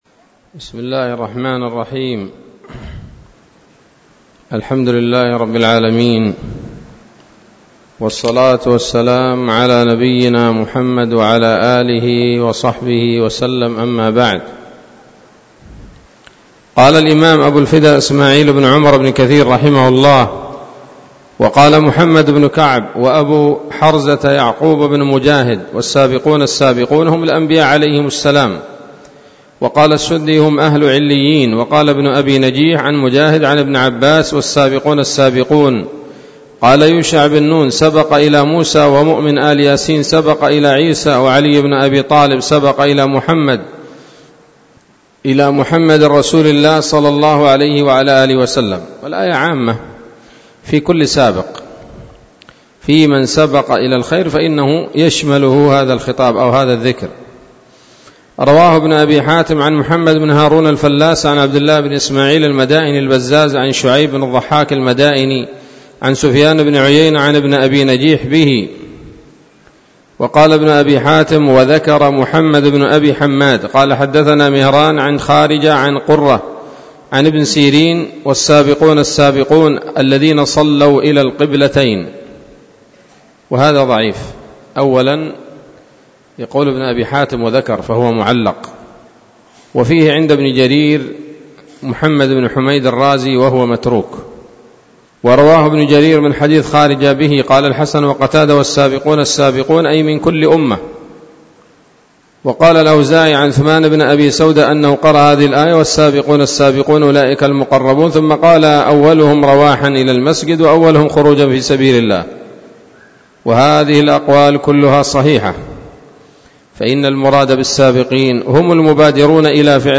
الدرس الثالث من سورة الواقعة من تفسير ابن كثير رحمه الله تعالى